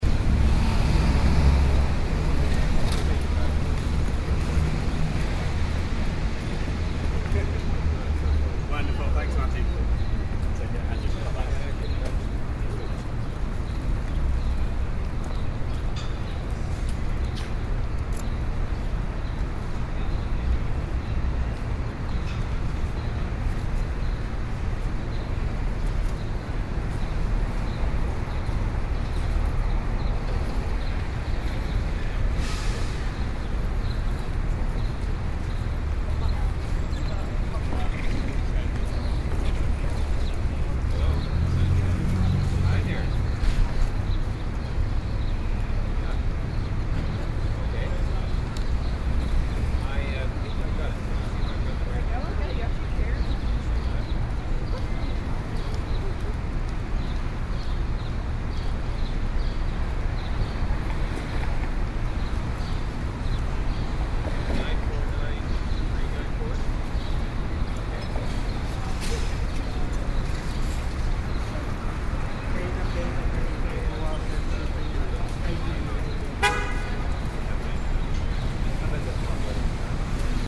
city.mp3